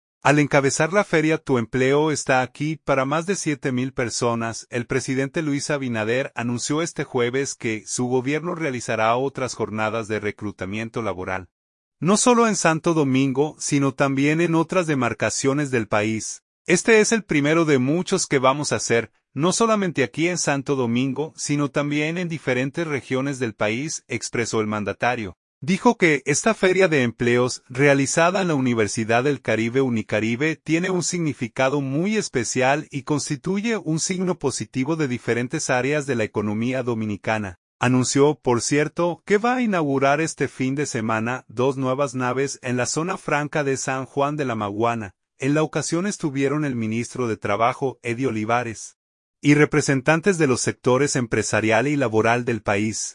Abinader, en gran feria de empleos: “Este es el primero de muchos que vamos hacer”
Dijo que esta feria de empleos, realizada en la Universidad del Caribe (Unicaribe), tiene un “significado muy especial” y constituye “un signo positivo de diferentes áreas de la economía dominicana”.